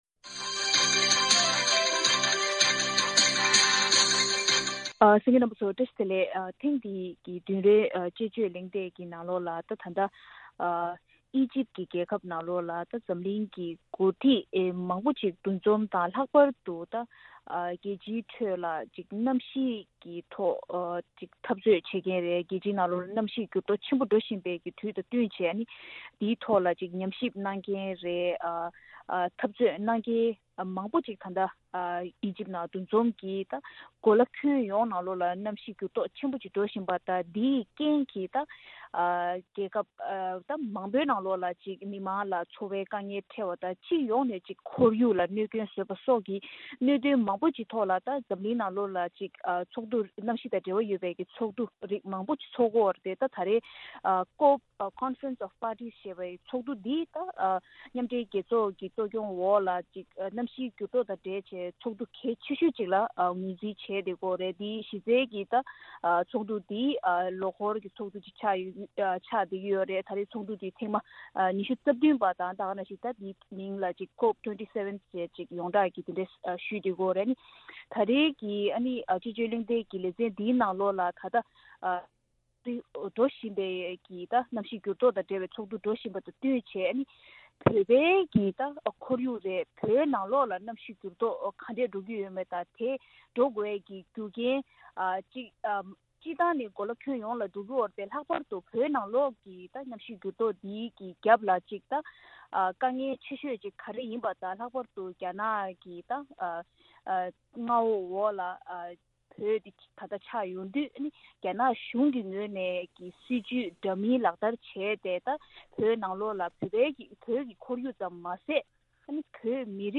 ཐེངས་འདིའི་བདུན་རེའི་དཔྱད་བརྗོད་ཀྱི་གླེང་སྟེགས་ལས་རིམ་ནང་།